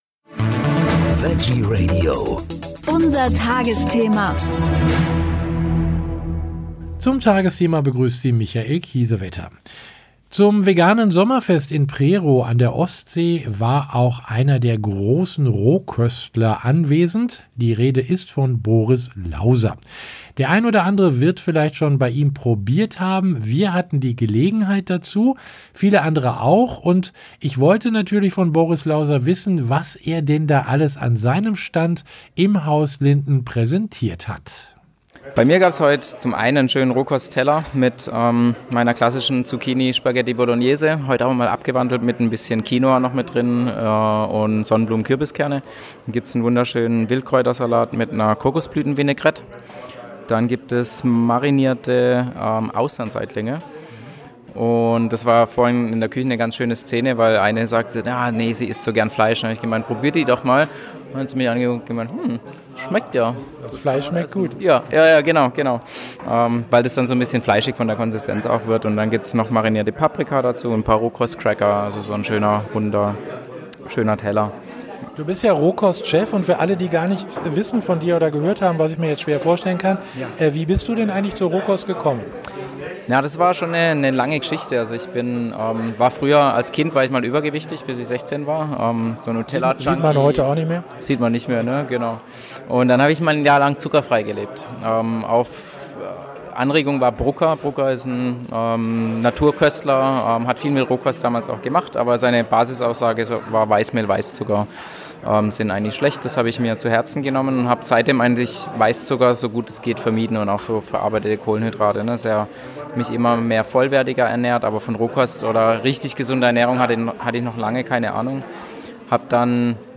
Auf dem veganen Sommerfest in Prerow an der Ostsee